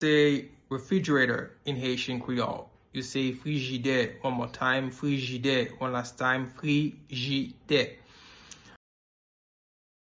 Pronunciation:
Listen to and watch “Frijidè” audio pronunciation in Haitian Creole by a native Haitian  in the video below:
22.How-to-say-Refrigerator-in-Haitian-Creole-–-Frijide-pronunciation-.mp3